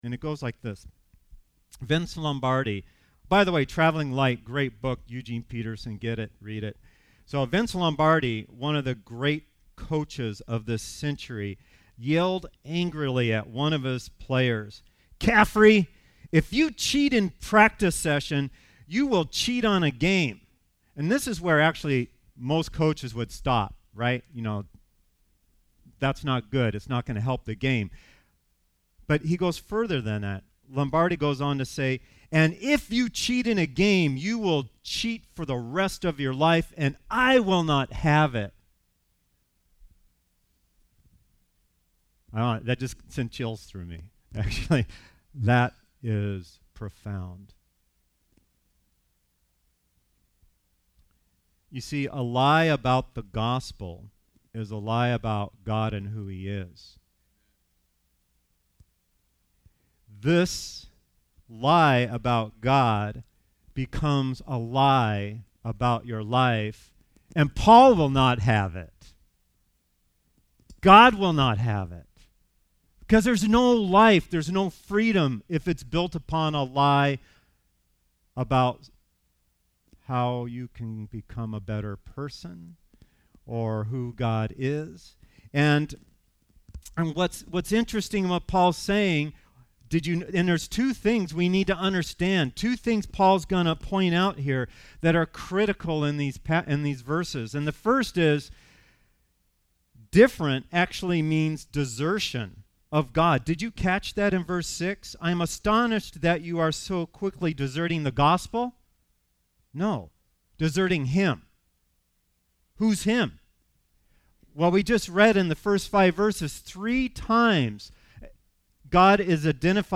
Due to technical difficulties, the beginning of the sermon is missing from the recording.